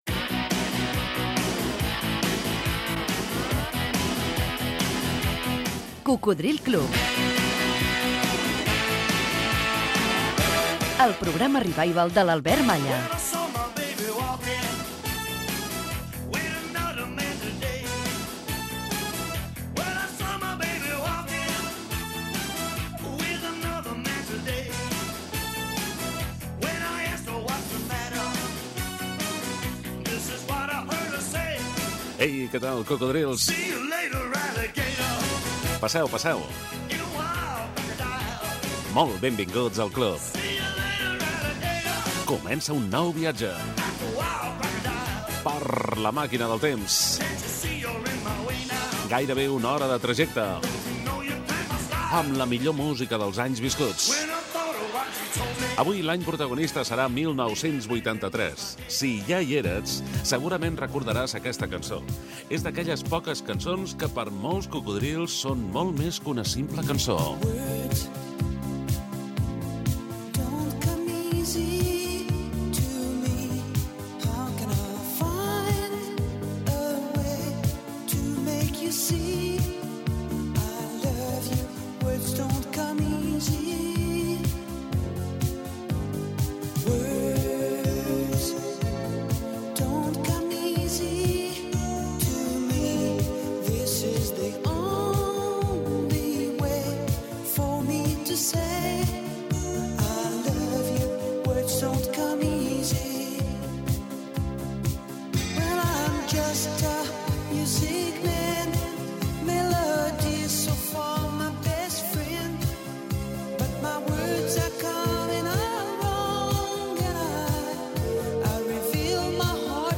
Cocodril club és el programa musical 'revival' més veterà de la ràdio.